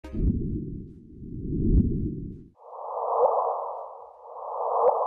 На этой странице собраны звуки черной дыры, преобразованные из электромагнитных и гравитационных волн.
Звук столкновения черных дыр и рождение гравитационных волн